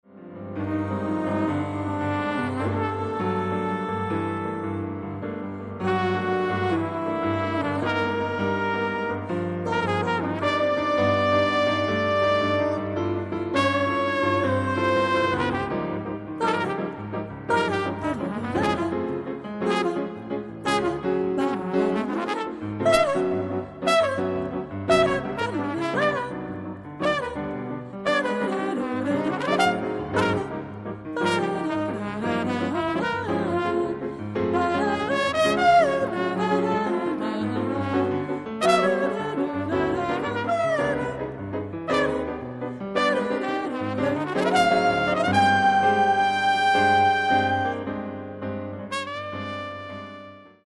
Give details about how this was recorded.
Recorded April 1994 at Rainbow Studio, Oslo.